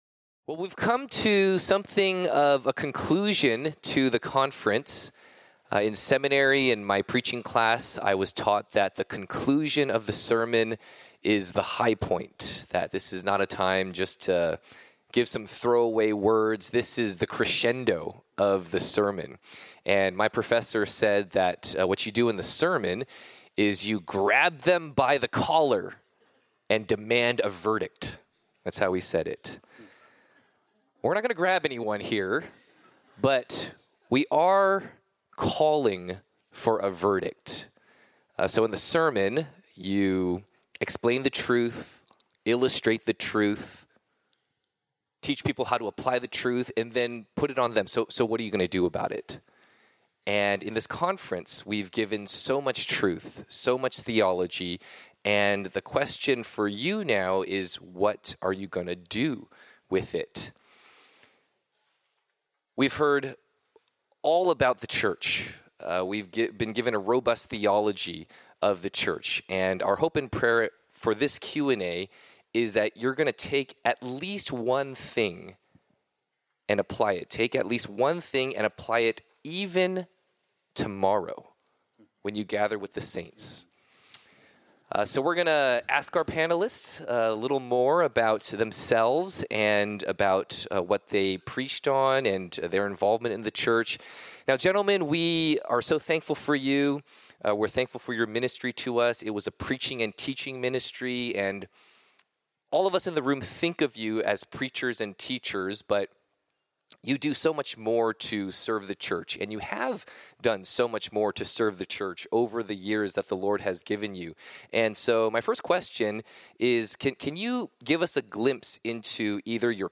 Session 9: Panel Q&A